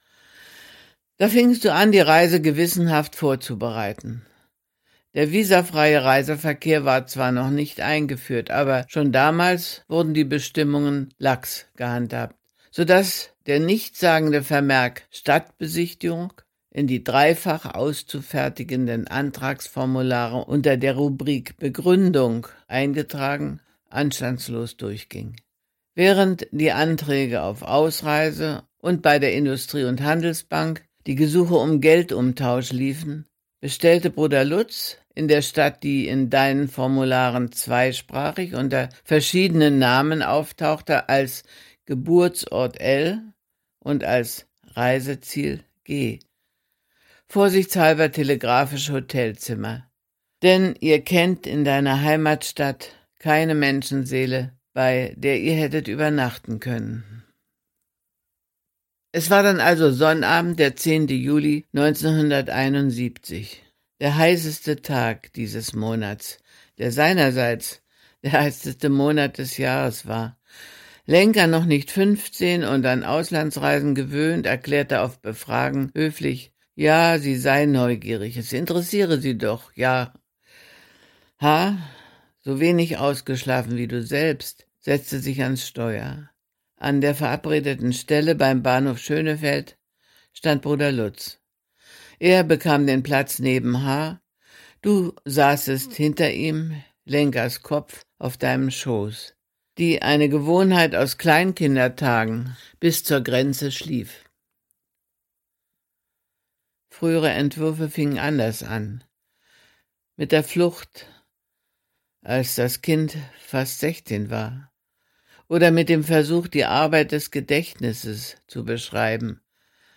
Kindheitsmuster - Christa Wolf - Hörbuch